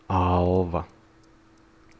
Ääntäminen
Synonyymit gris Ääntäminen France Tuntematon aksentti: IPA: /e.tɛ̃/ Haettu sana löytyi näillä lähdekielillä: ranska Käännös Ääninäyte Substantiivit 1. alva {f} Suku: m .